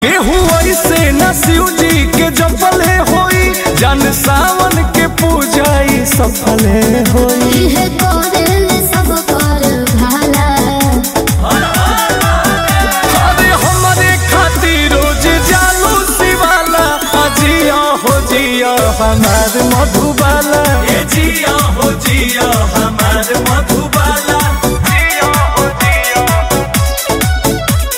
Bhojpuri Bolbum Ringtones